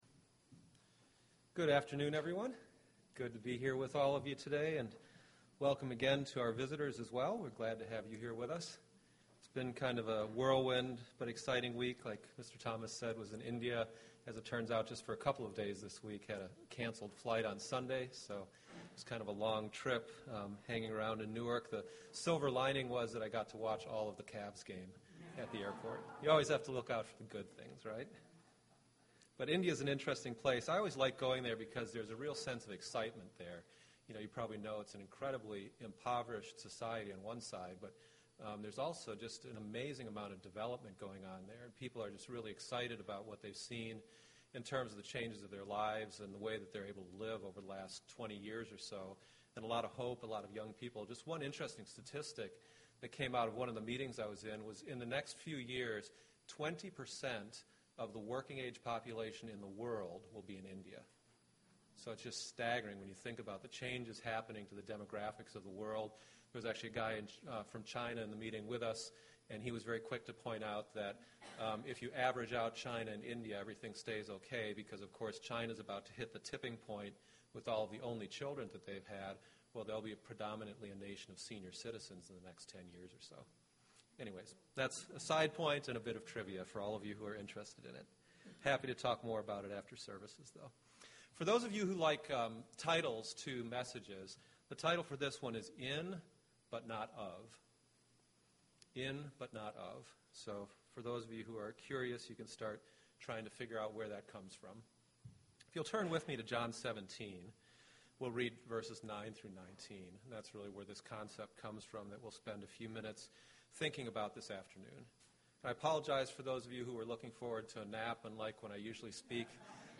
This can seem like a paradox, but it really isn't. Let's take a look and see how we should be living in this world but not be of this world UCG Sermon Transcript This transcript was generated by AI and may contain errors.